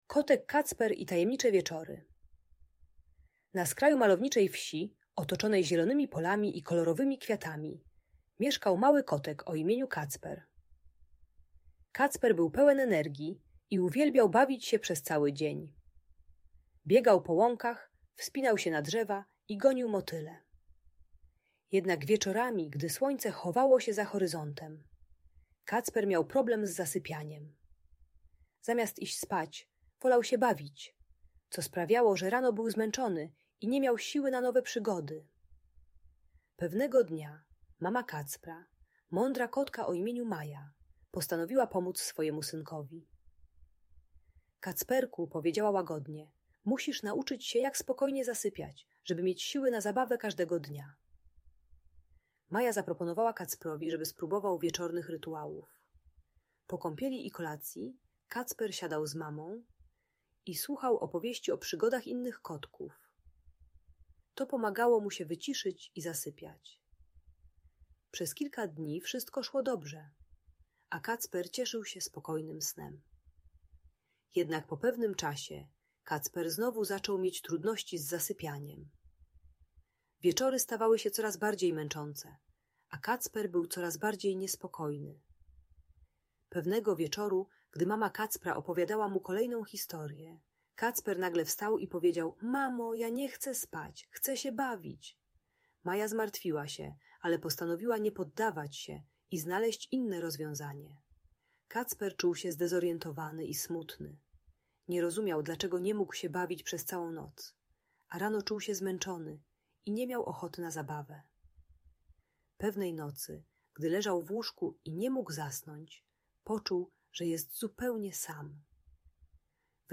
Historia o Kocie Kacprze i Jego Wieczornych Rytuałach - Audiobajka